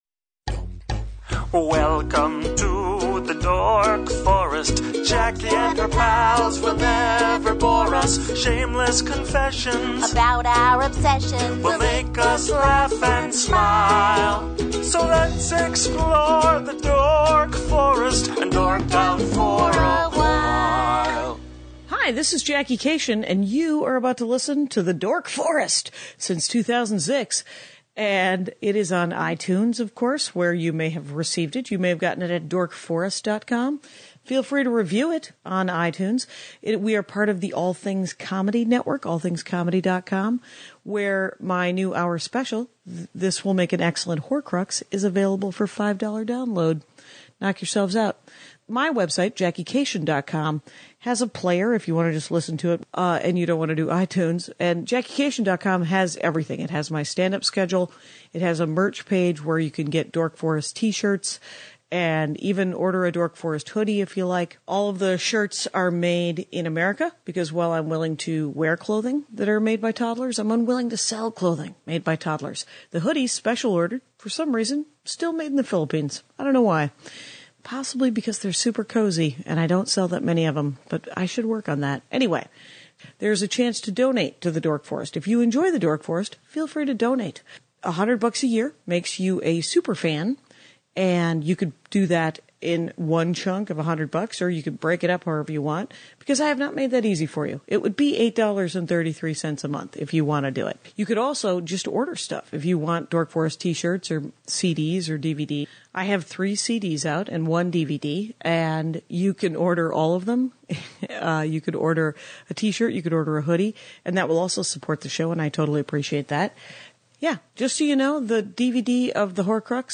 We talk romance novels. She is a little quiet on the mic but it's totally worth it.